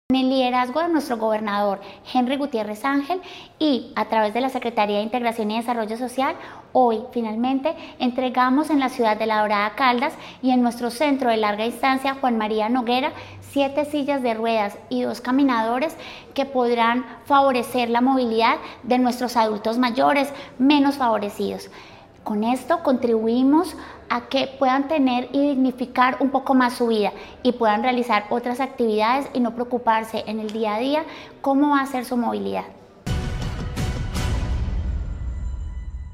Sandra Patricia Álvarez Castro, secretaría de Integración y Desarrollo Social de Caldas.